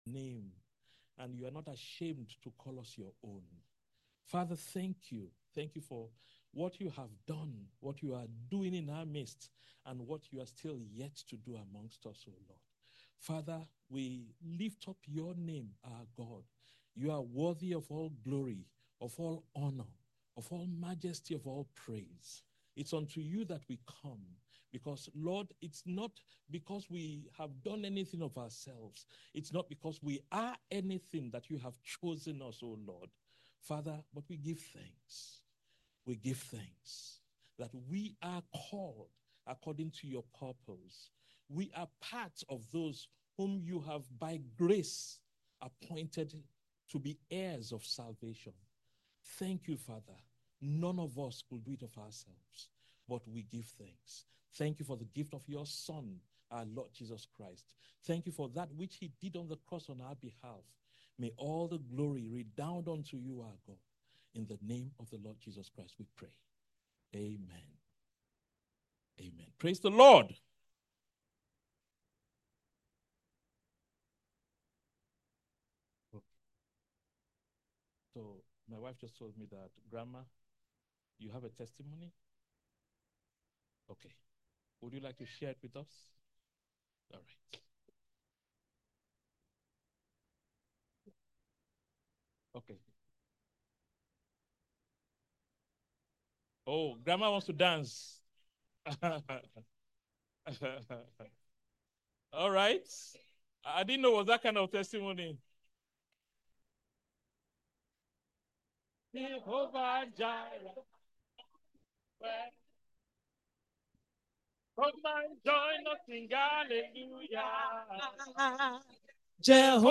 From Series: "Sermons"